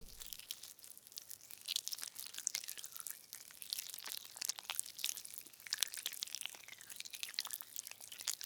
Звуки скорпиона: хруст и шелест при поедании добычи